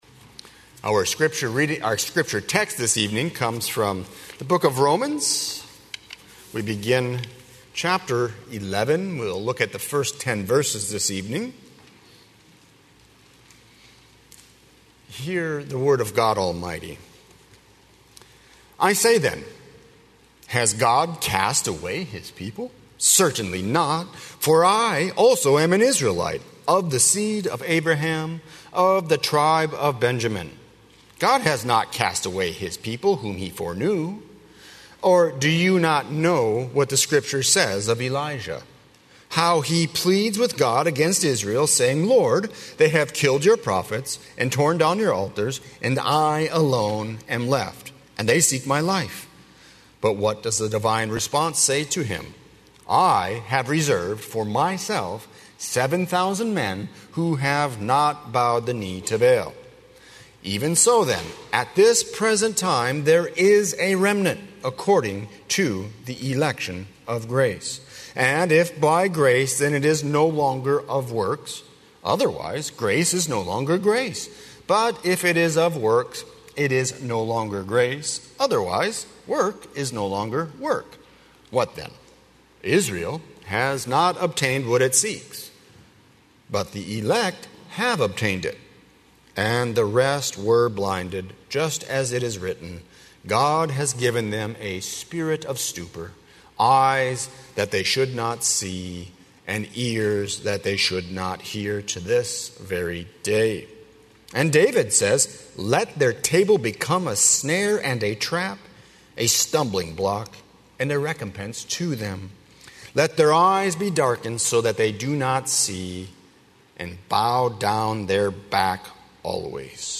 00:00 Download Copy link Sermon Text Romans 11:1–10